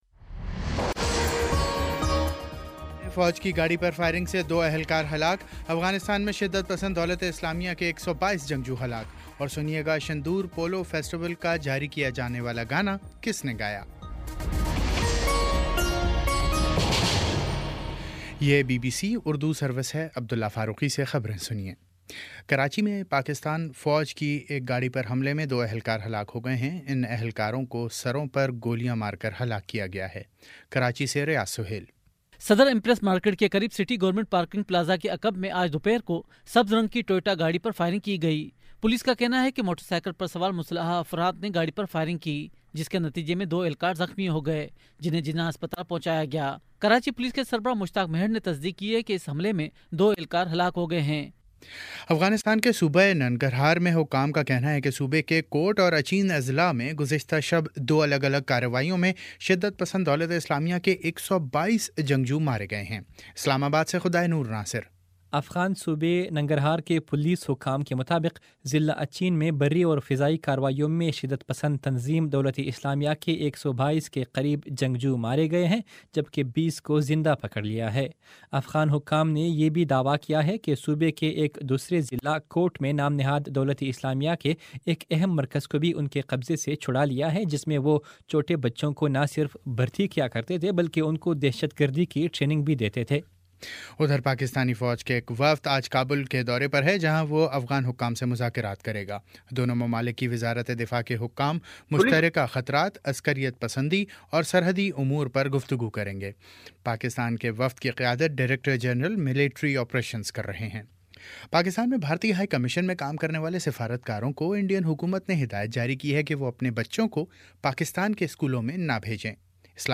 جولائی 26 : شام چھ بجے کا نیوز بُلیٹن